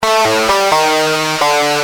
Lead_b4.wav